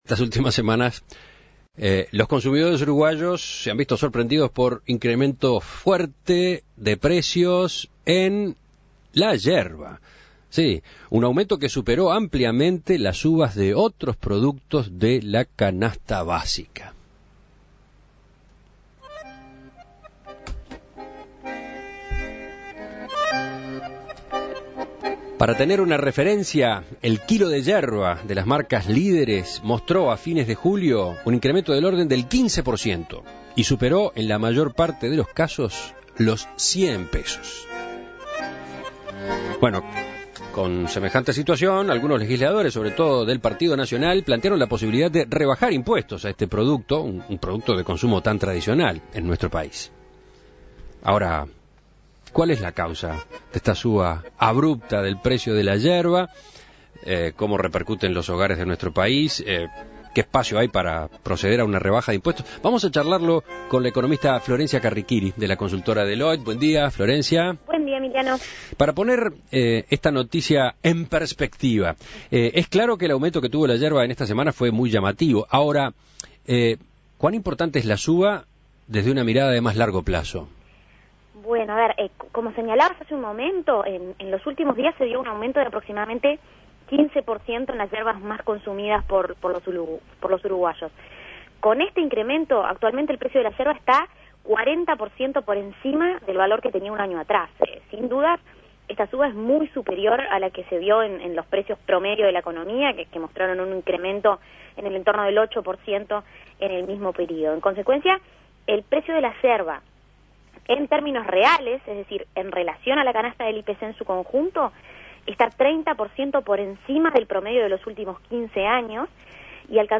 Análisis Económico El precio de la yerba tuvo un aumento abrupto en las últimas semanas.